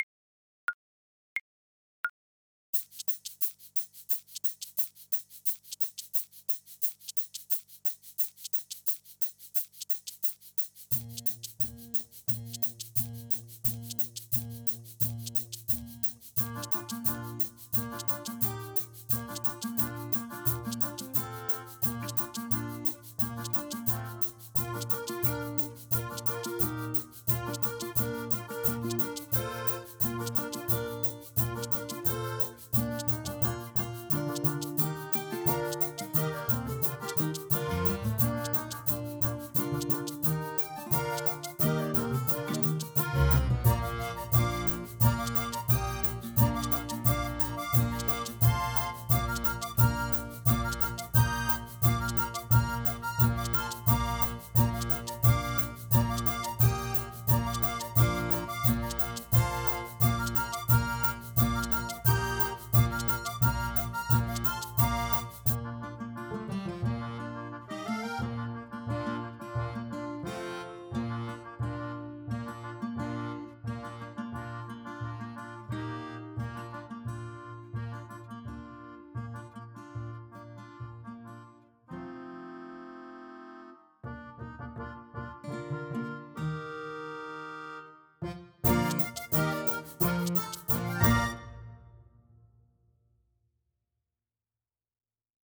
Clarinet
Traditional Music of unknown author.
Allegro Energico = c.88 (View more music marked Allegro)
2/2 (View more 2/2 Music)
Bb major (Sounding Pitch) C major (Clarinet in Bb) (View more Bb major Music for Clarinet )